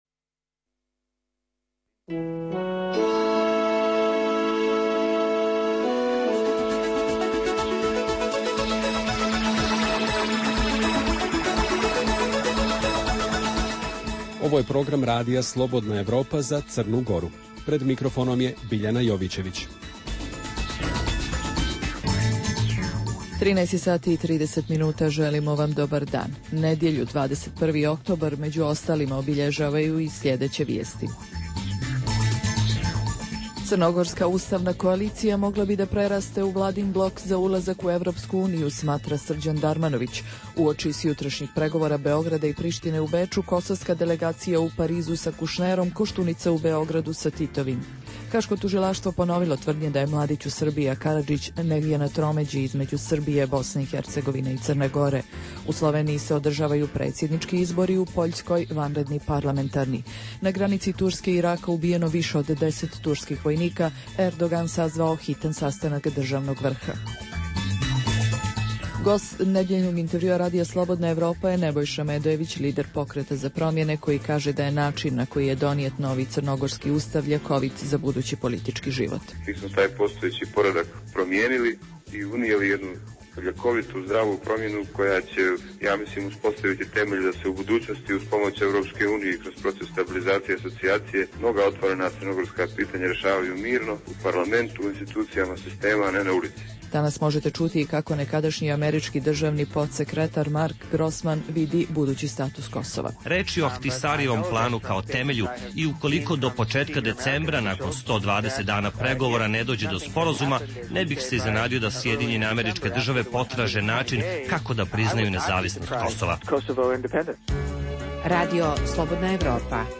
U nedjeljnoj emisiji objavljujemo, uz ostalo: U intervjjuu za RSE lider opozicionog Pokreta za promjene Nebojša Medojević komentariše značaj usvajanja Ustava Crne Gore, te mogućnost saradnje sa strankama koje su Pokret optužile za izdaju srpskih interesa. Bavimo se i najznačajnijom regionalnom temom - Kosovom.